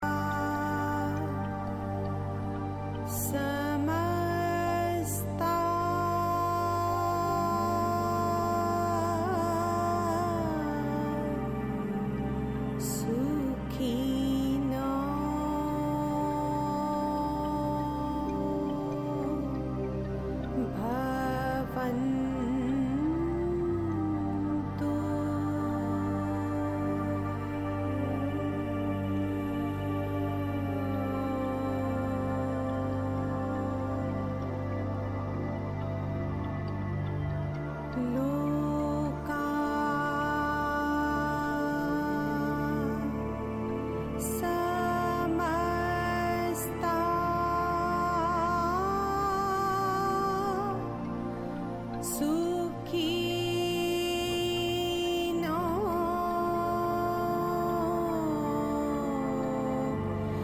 Deep Meditative journey
chants and mantras